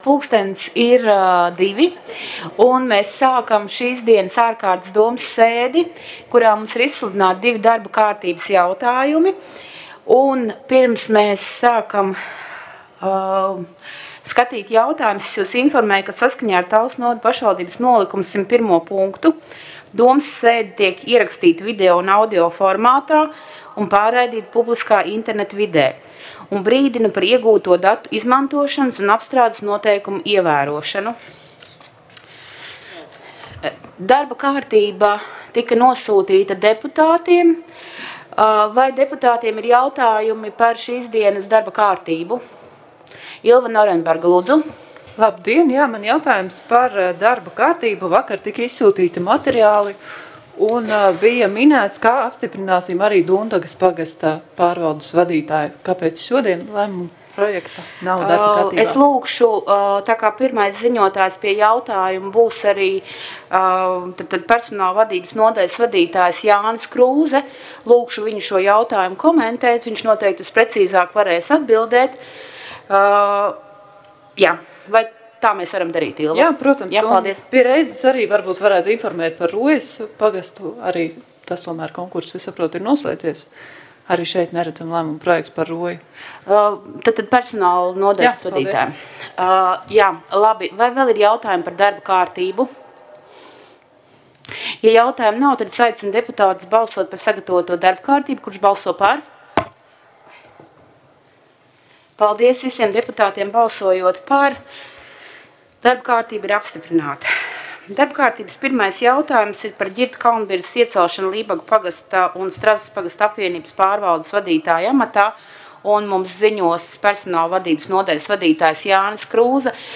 Balss ātrums Publicēts: 20.01.2022. Protokola tēma Domes sēde Protokola gads 2022 Lejupielādēt: 1.